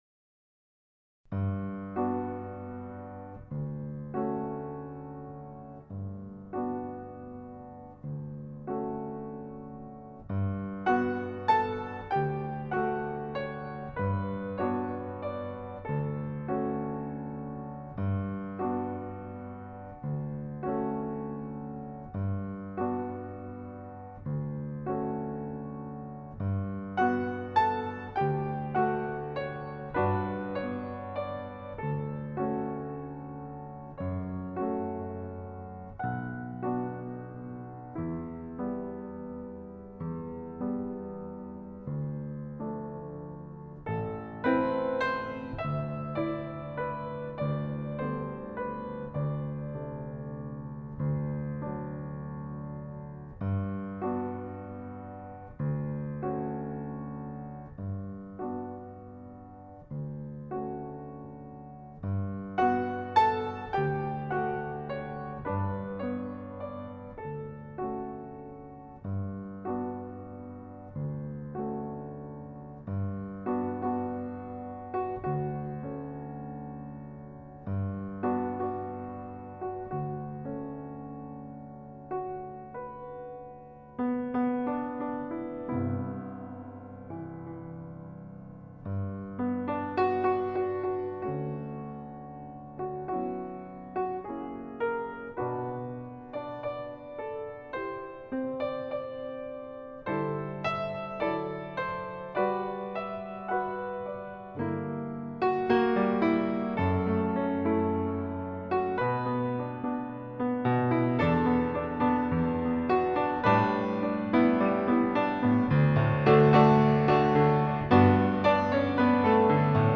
Another eclectic mix of solo
piano recordings, including